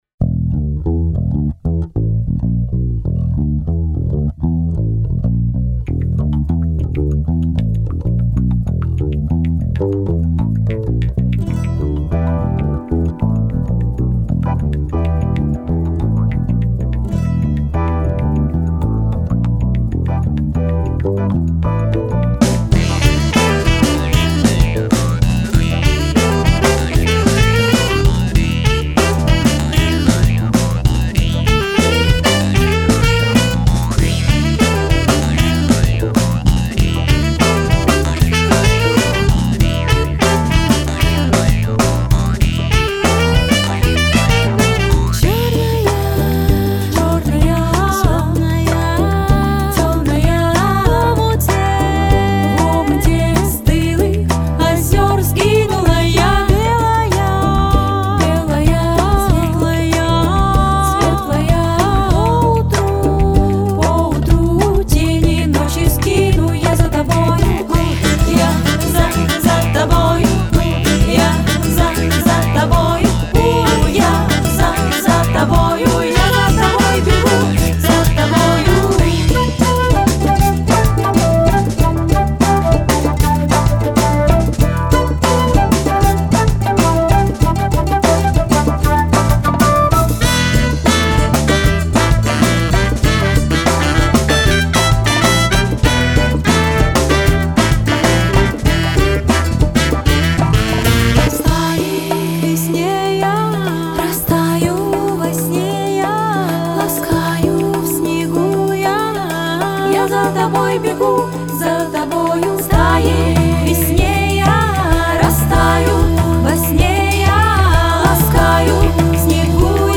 Style: Folk